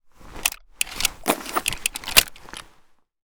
aps_reload.ogg